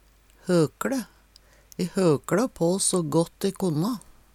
høkLe - Numedalsmål (en-US)